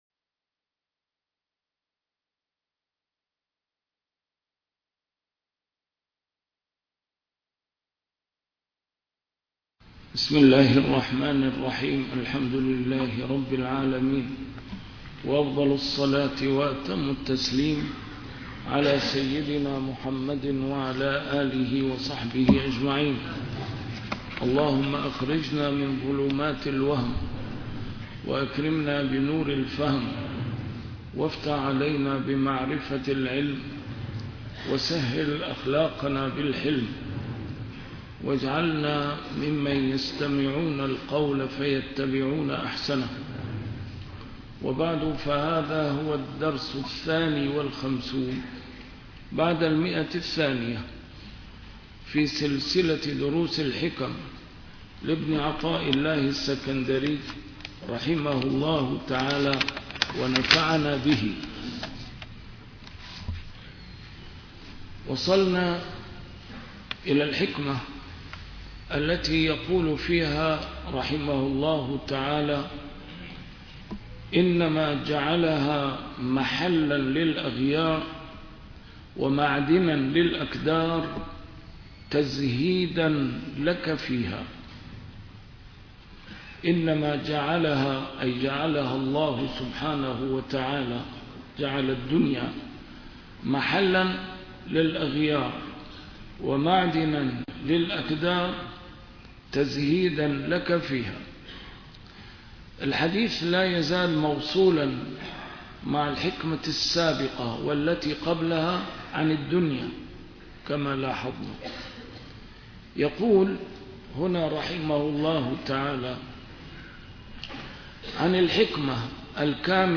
A MARTYR SCHOLAR: IMAM MUHAMMAD SAEED RAMADAN AL-BOUTI - الدروس العلمية - شرح الحكم العطائية - الدرس رقم 251 شرح الحكمة رقم 228